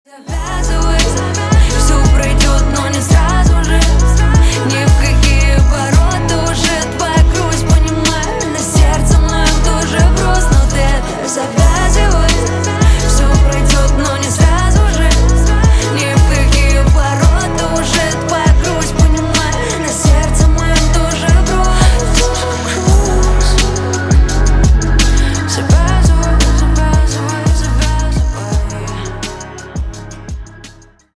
на русском на парня грустные